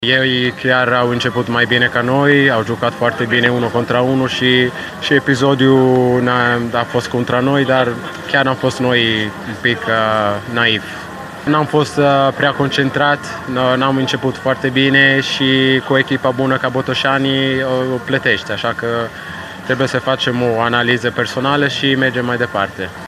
Declarațiile ”la cald” din tabăra Bătrânei Doamne au scos în evidență startul ratat de întâlnire, care a fost până la urmă decisiv în stabilirea învingătoarei.